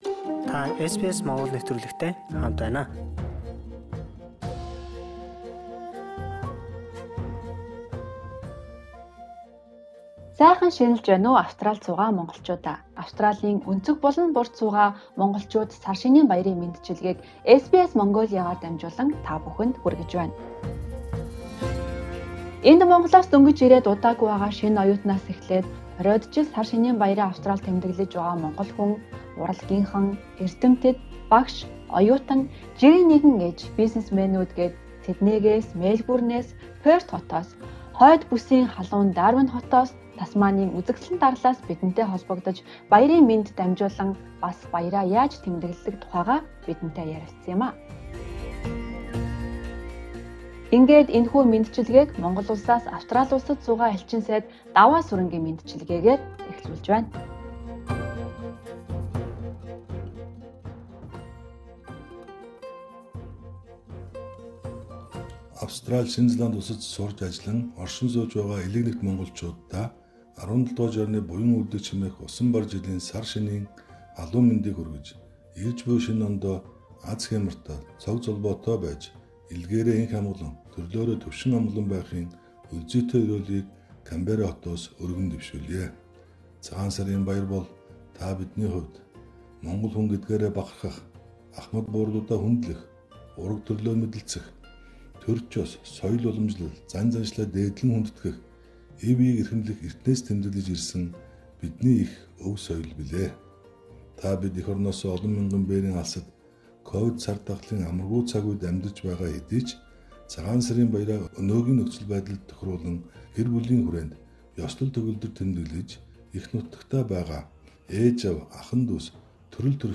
Австралийн өнцөг булан бүрт суугаа Монголчууд сар шинийн баярыг хэрхэн тэмдэглэдэг тухай өөрсдийн туршлагаа хуваалцаж, мэндчилгээ хүргэж байна.